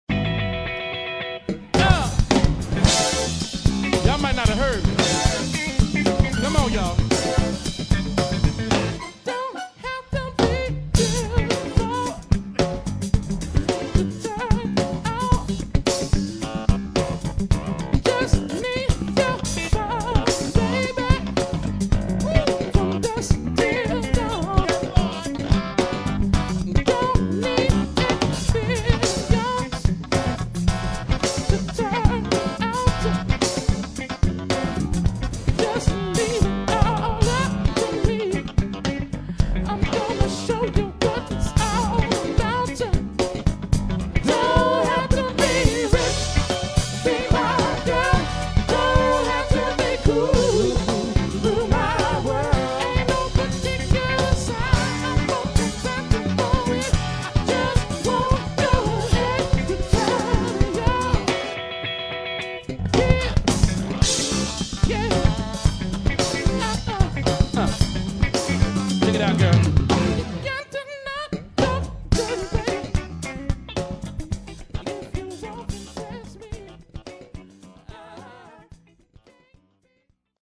funk and R&B